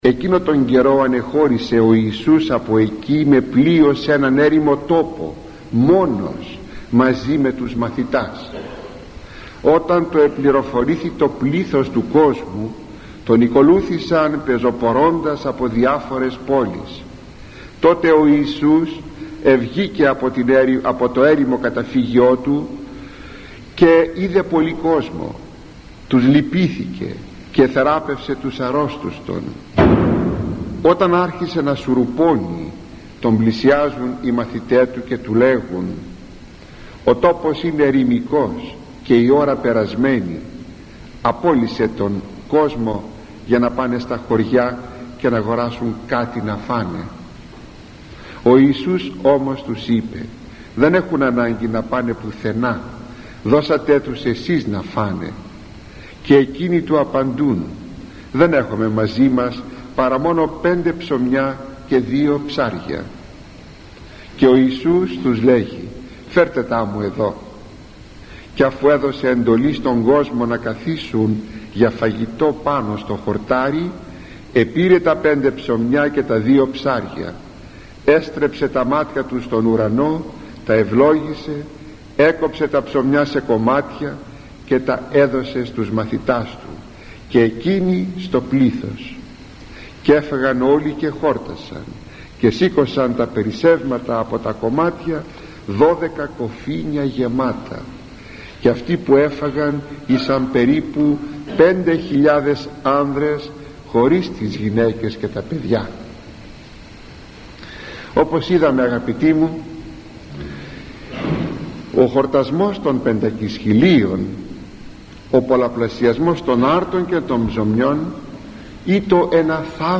ηχογραφημένη ομιλία
Ο λόγος του ήταν πάντοτε μεστός, προσεγμένος, επιστημονικός αλλά συνάμα κατανοητός και προσιτός, ακόμη και για τους πλέον απλοϊκούς ακροατές του.